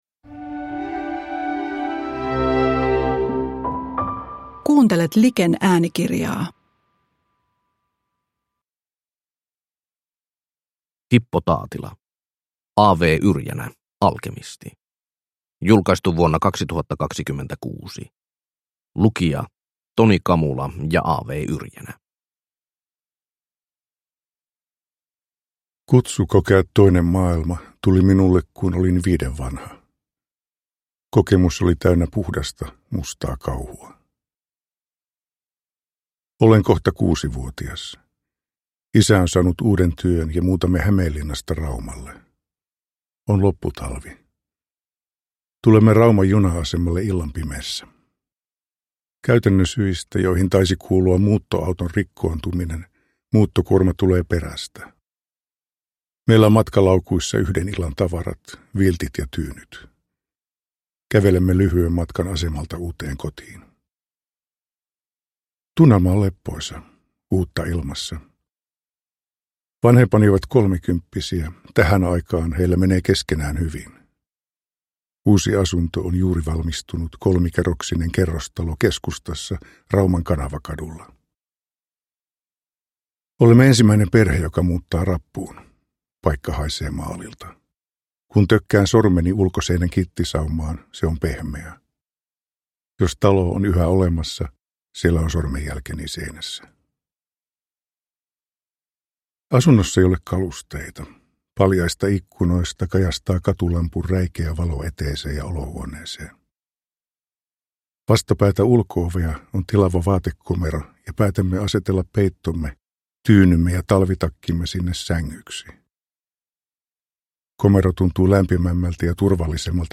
A. W. Yrjänä, Alkemisti – Ljudbok